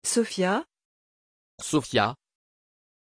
Prononciation de Sophia
pronunciation-sophia-fr.mp3